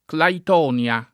[ klait 0 n L a ]